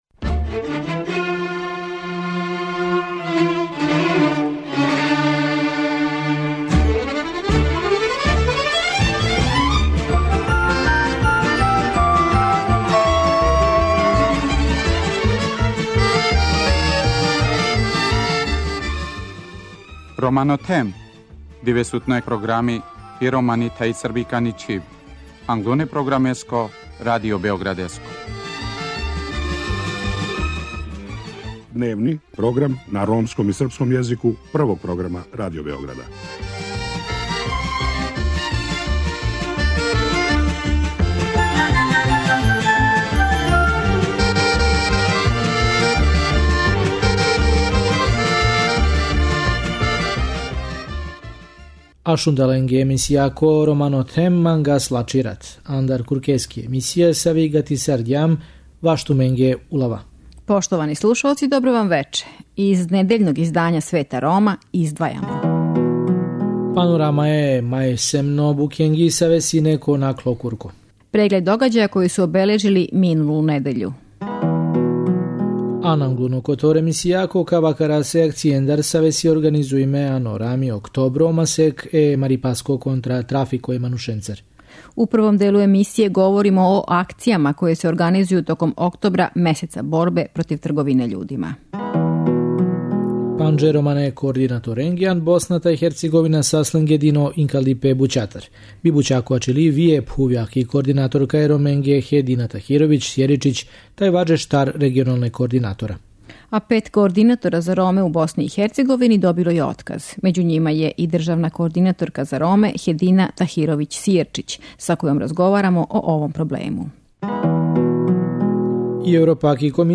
Међу њима је и државна кородинаторка за Роме Хедина Тахировић -Сијерчић са којом разговарамо о овом проблему.